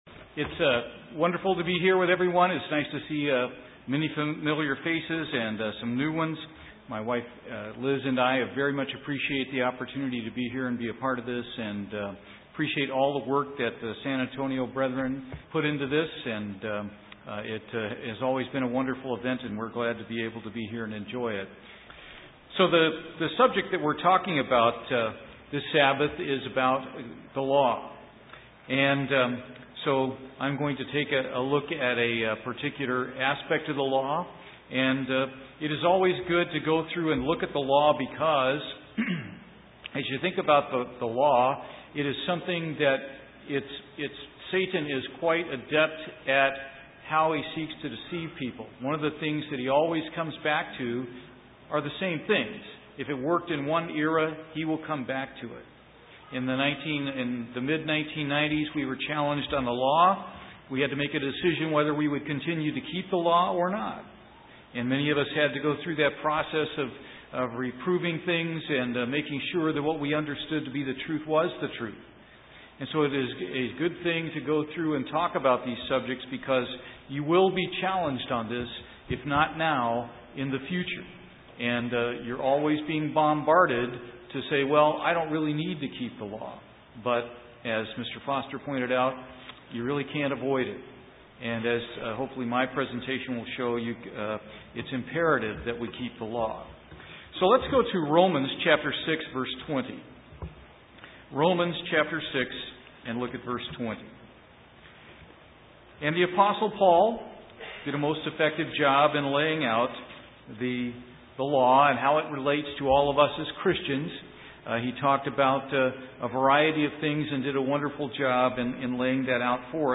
Sermons
Given in San Antonio, TX